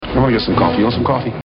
Tags: Celebrity Actor Tommy Lee Jones Tommy Lee Jones Audio clips Movie